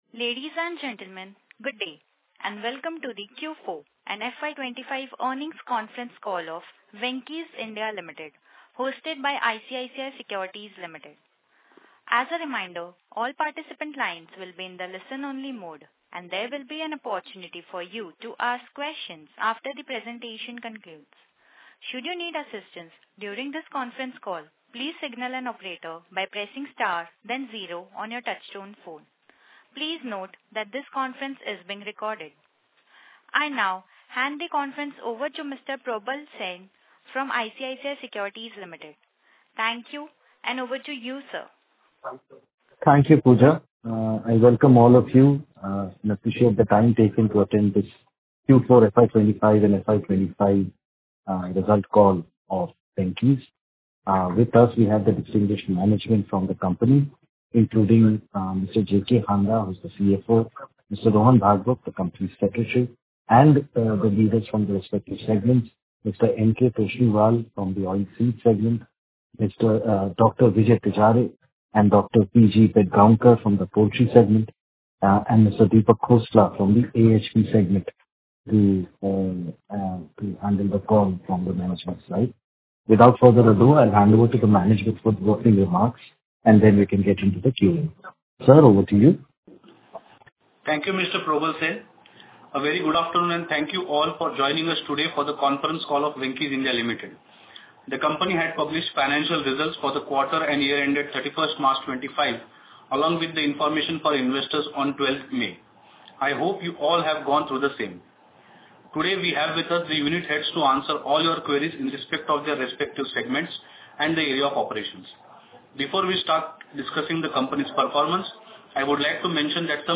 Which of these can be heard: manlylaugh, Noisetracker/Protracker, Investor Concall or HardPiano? Investor Concall